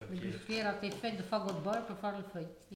Mots Clé fagot(s) ; bois de chauffage, charbon ; Localisation Saint-Jean-de-Monts
Catégorie Locution